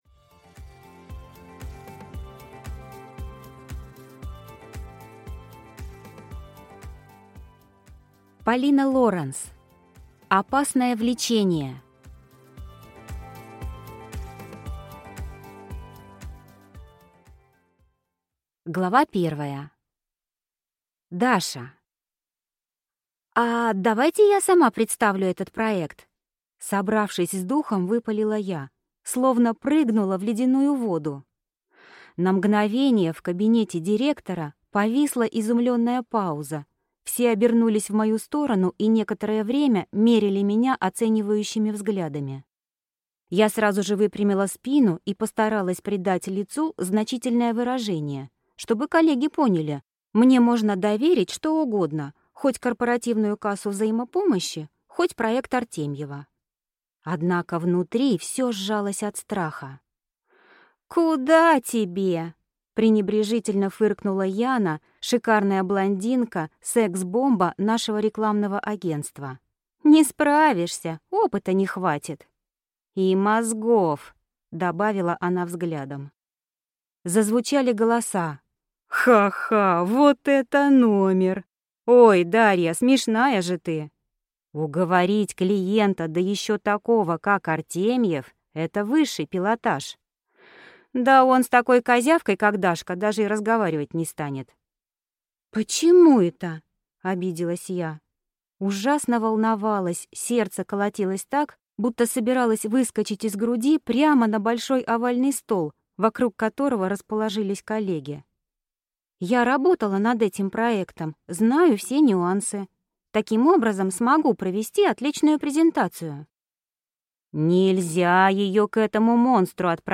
Аудиокнига Опасное влечение | Библиотека аудиокниг